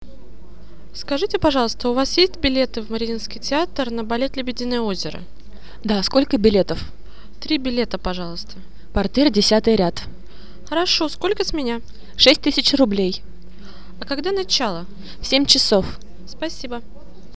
Lyssna på diskussionen vid biljettkassan och svara därefter på frågrona.